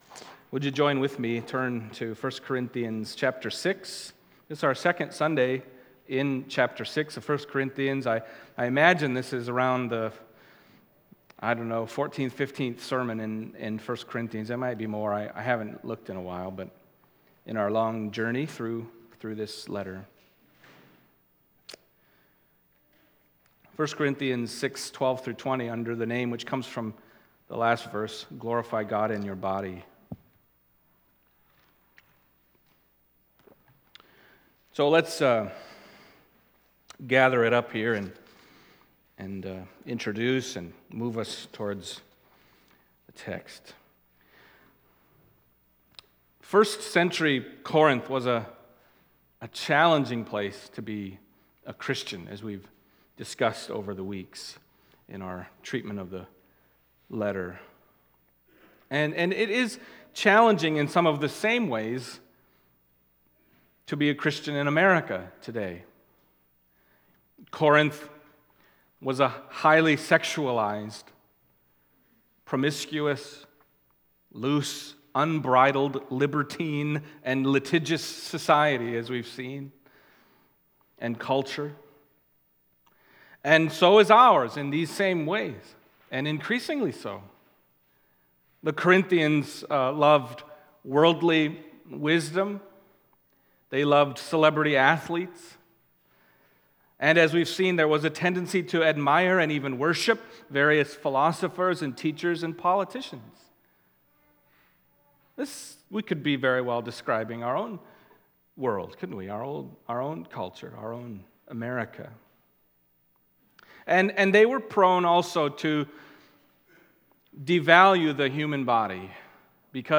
1 Corinthians 6:12-20 Service Type: Sunday Morning 1 Corinthians 6:12-20 « And Such Were Some of You The Beatitudes